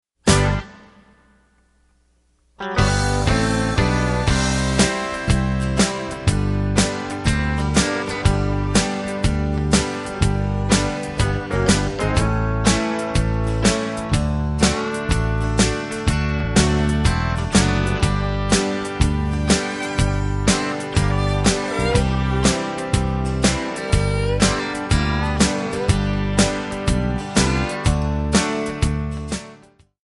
Backing track files: Duets (309)